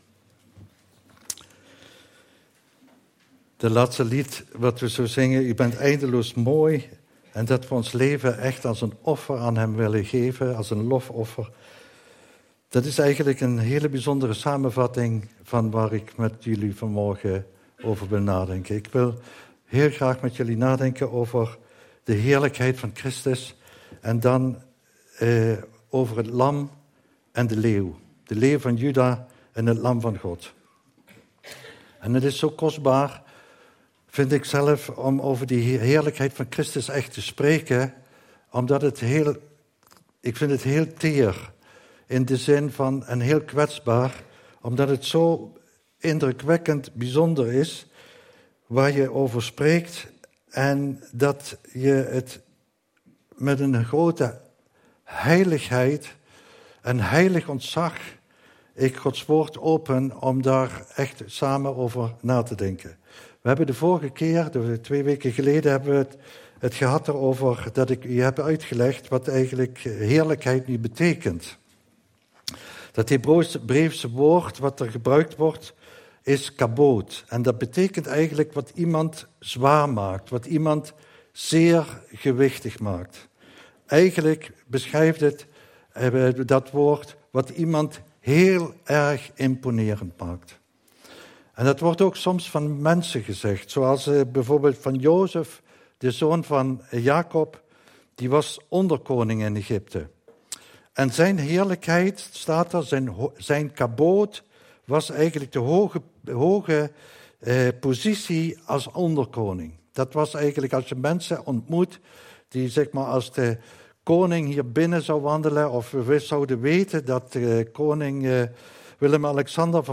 Passage: Openbaring 4, Openbaring 5 Dienstsoort: Eredienst